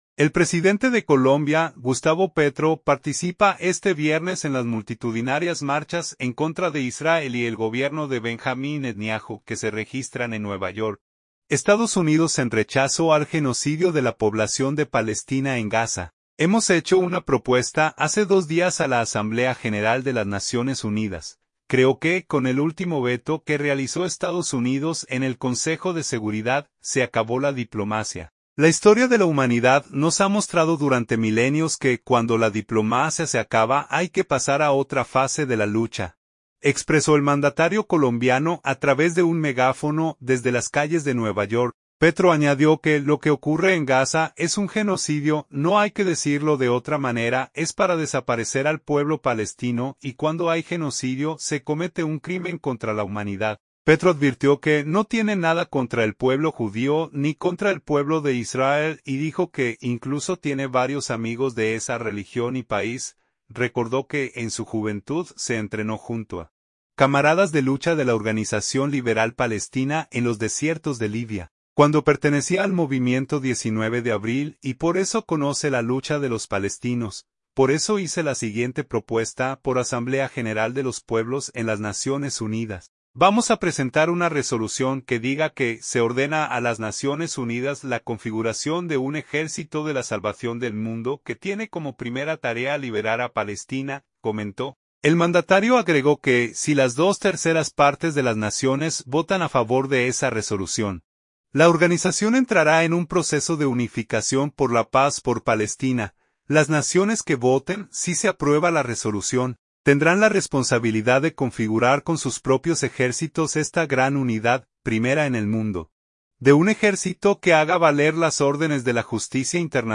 El presidente de Colombia, Gustavo Petro, participa este viernes en las multitudinarias marchas en contra de Israel y el Gobierno de Benjamín Netanyahu, que se registran en Nueva York, EE.UU., en rechazo al «genocidio» de la población de Palestina en Gaza.
«Hemos hecho una propuesta hace dos días a la Asamblea General de las Naciones Unidas. Creo que con el último veto que realizó EE.UU. en el Consejo de Seguridad, se acabó la diplomacia. La historia de la humanidad nos ha mostrado durante milenios que cuando la diplomacia se acaba hay que pasar a otra fase de la lucha«, expresó el mandatario colombiano a través de un megáfono desde las calles de Nueva York.